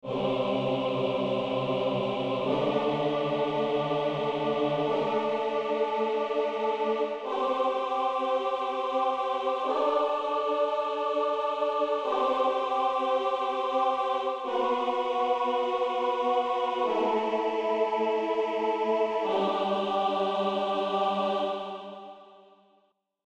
Sin-tritono.mp3